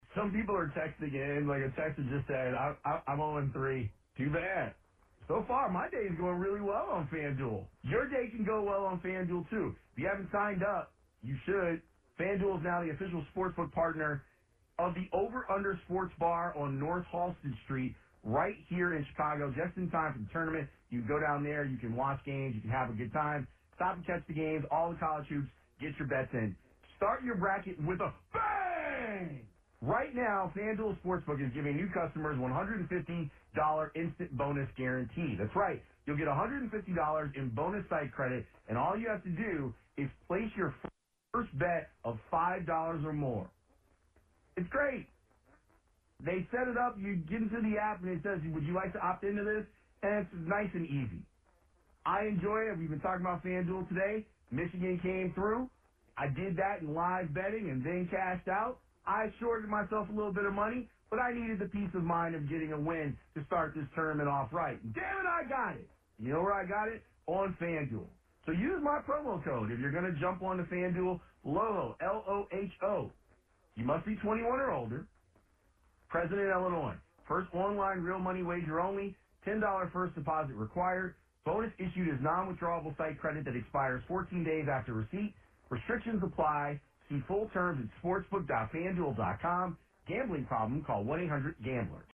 Live reads are ads that are read live on-the-air by a producer or radio host talent.
Creative Examples of Live Read Ads: